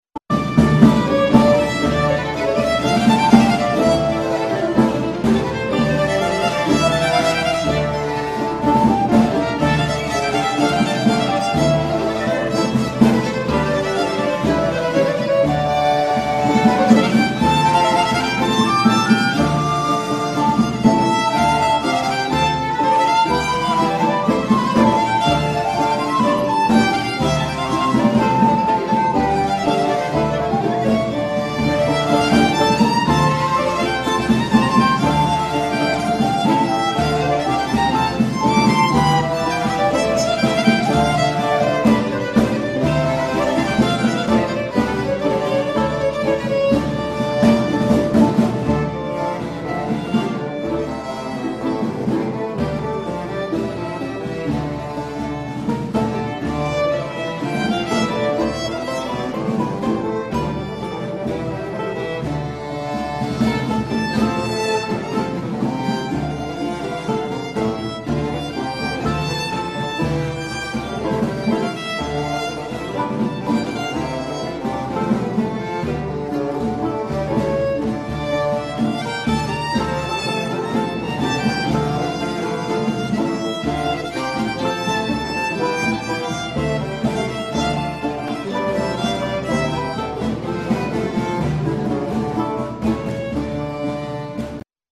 Pavane
Group: Dance Origin: Basse danse Influenced: Galliard , Allemande , Courante , Tombeau Synonyms: Павана , pavan, paven, pavin, pavian, pavine, pavyn A slow processional dance common in Europe during the 16th century (Renaissance).
Renaissance Dance, Pavane.mp3